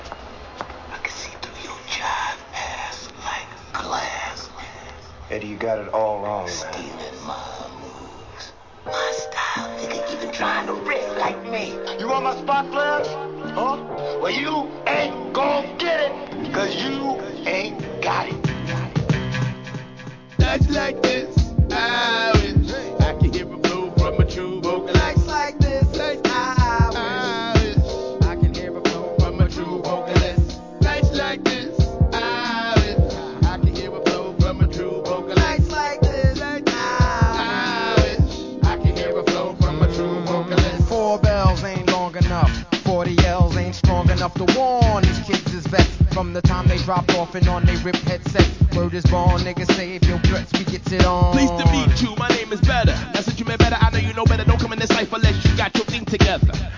HIP HOP/R&B
使いに浮遊感あふれる上音が効いた1997年リリース